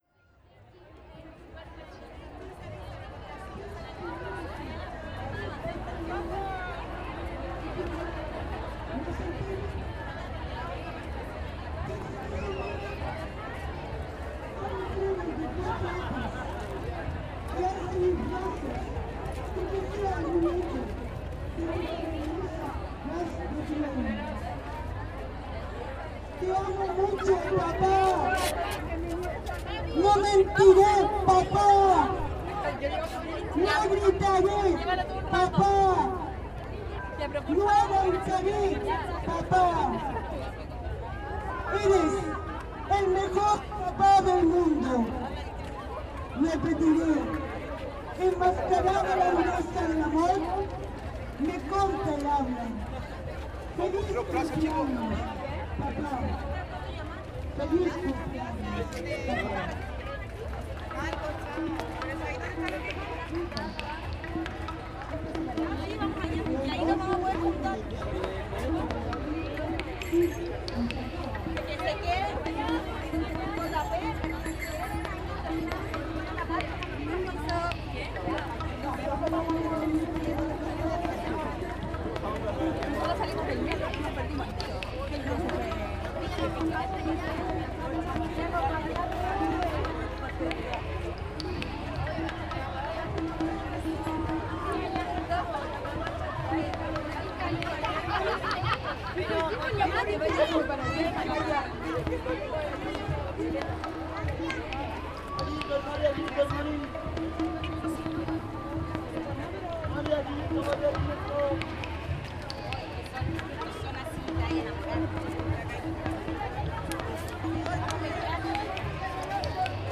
Le 8 mars dernier, près de 200 000 personnes, majoritairement des femmes, ont participé à la Marcha Feminista dans le cadre de la Journée internationale des droits des femmes. C’était extraordinaire et émouvant de suivre auprès de ces femmes la narration que produit une marche aussi gigantesque, parmi les sons de plus en plus forts, violents, enragés et enjoués. Cette cadence, ces harmonies et ces clameurs me sont restées dans la tête, des jours durant.
Son de la Marcha Feminista, 8 mars 2019, Plaza Italia et Alameda, Santiago